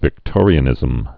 (vĭk-tôrē-ə-nĭzəm)